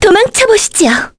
Selene-Vox_Skill3-2_kr.wav